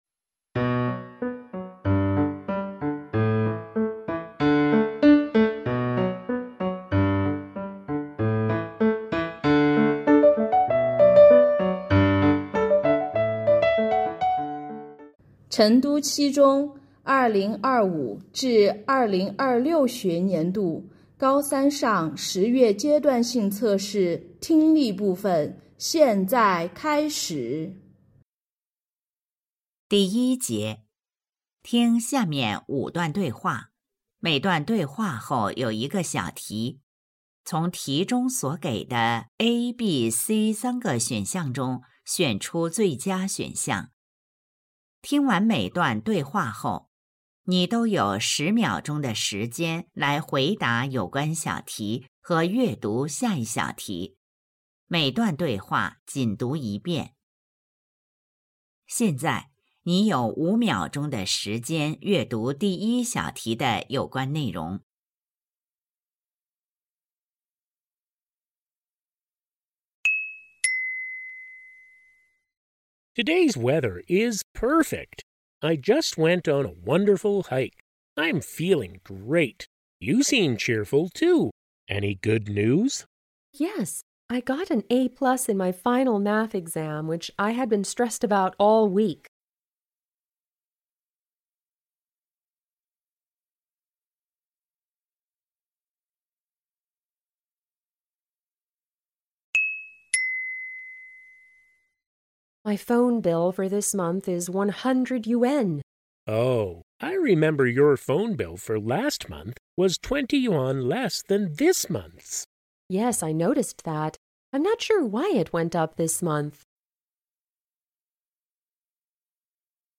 成都七中2025-2026学年高三上学期十月阶段性检测英语听力.mp3